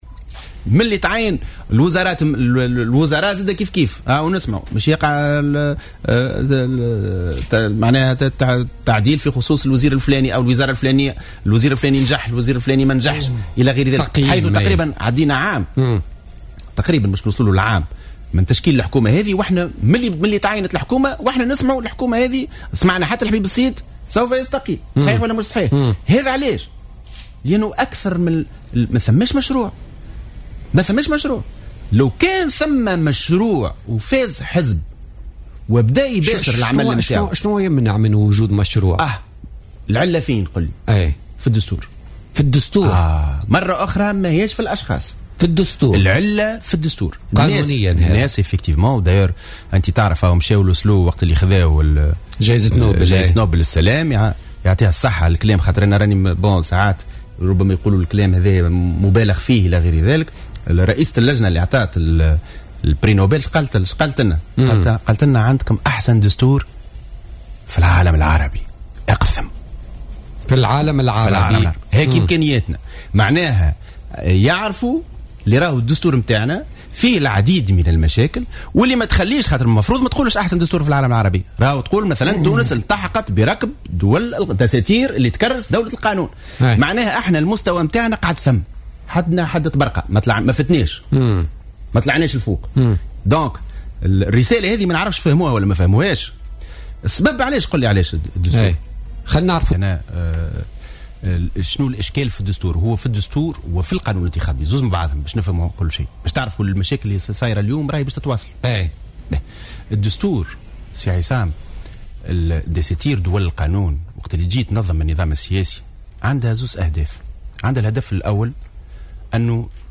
وأضاف في مداخلة له اليوم في برنامج "بوليتيكا" أن الحديث الدائم عن تحويرات وزارية يكشف عن غياب برامج واضحة للأحزاب السياسية ويدعو إلى القيام بتعديل القانون الانتخابي والتخلي عن طريقة التمثيل النسبي.